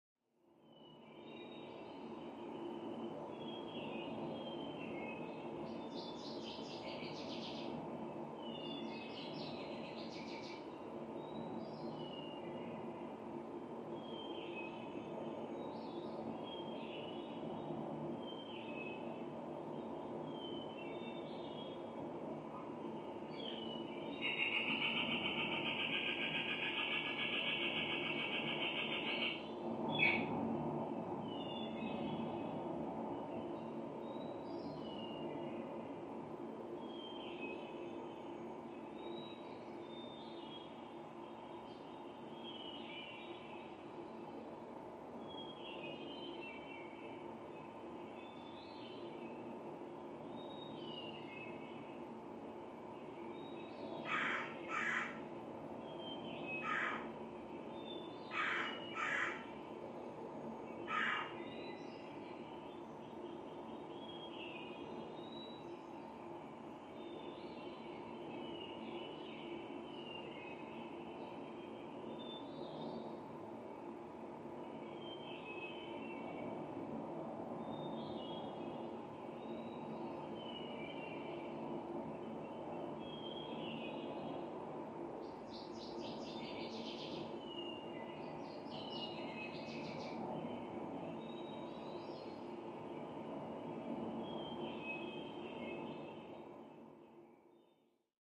Birds; Day Ambience, Through Open Window.